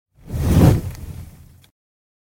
Fireball Whoosh Sound Effect
Launch a blazing fireball with a dramatic fiery swoosh, perfect for ancient weapons, games, animations, and cinematic projects. Add intense, magical audio impact to your videos and multimedia.
Fireball-whoosh-sound-effect.mp3